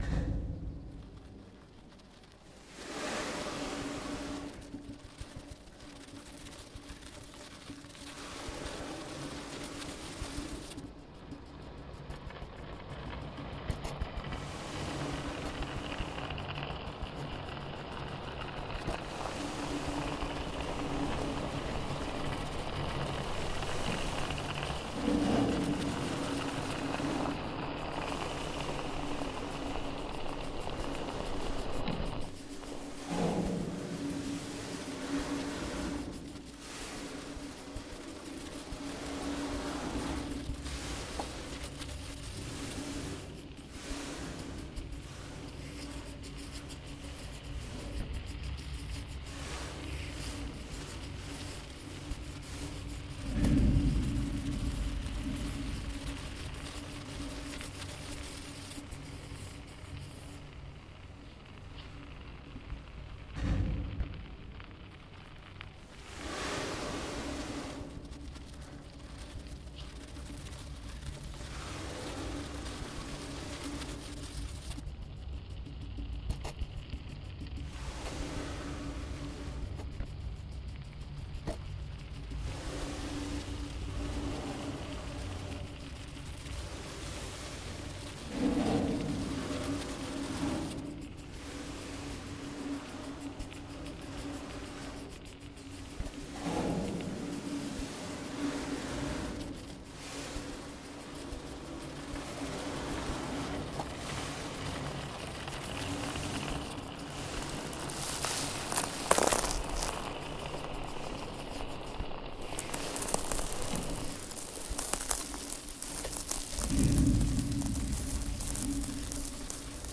I was playing with the materials on top of a wooden table and a drumhead. There were certain improvised gestures inspired by the nature and state of the sand.
What was happening during these recordings was simply playing with the possibilities of sand that moves on the plate/drumhead. I tried to create different sound qualities, rhythm, melody, patterns and textures. When composing in Ableton, I played with simple ideas and basically studied the program while doing.
When I am listening to the sample it reminds me of the water, the movement of the waves.
Swipes toward and away or passing the microphone, fingers swirling, fumbling, dropping on top of the sand and tapping the surface.